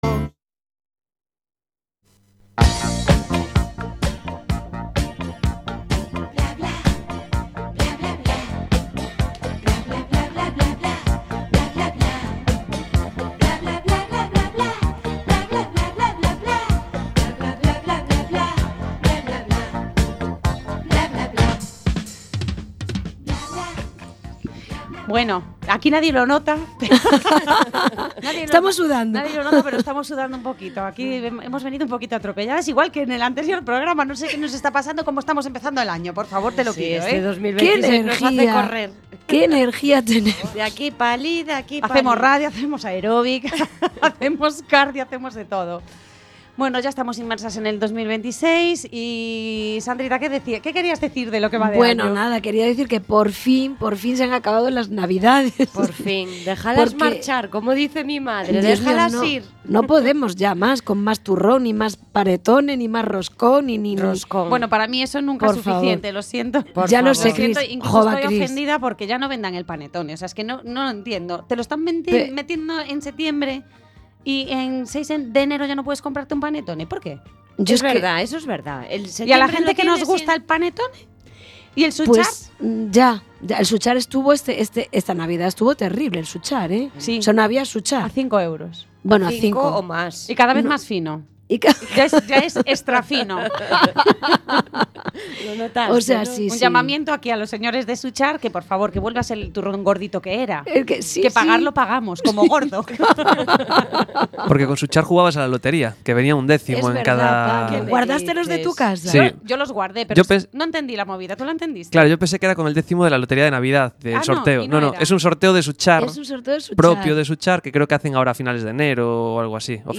Conversamos sobre la fotografía como memoria, como testimonio y como forma de estar presente, repasando historias, paisajes y encuentros que dejaron huella en su camino.